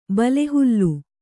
♪ bale hullu